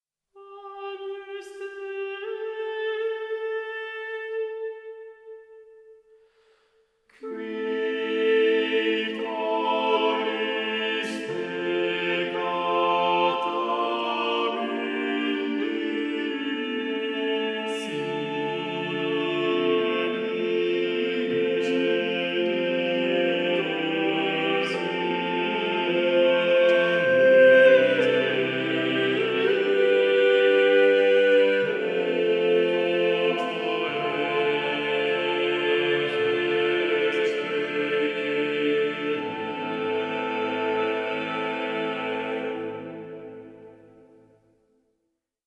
Renaissance Vokal
countertenor
tenor
baritone
bass